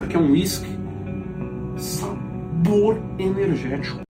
sabor energetico Meme Sound Effect